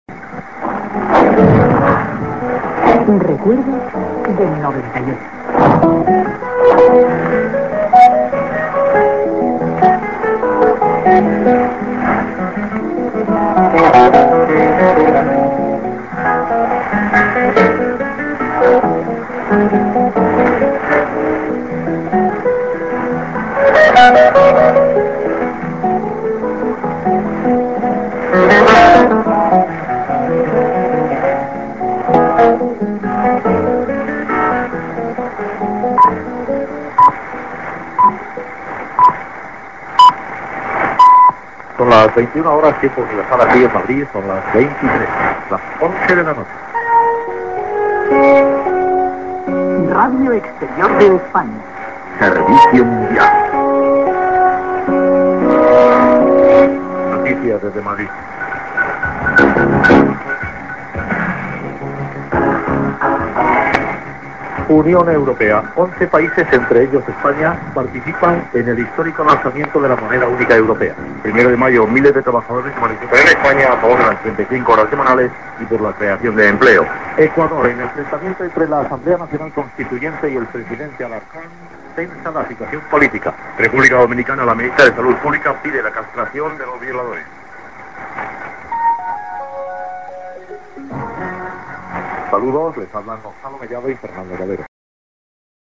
->music->TS->ID(man)->IS+ID(women+man)->ID(man)->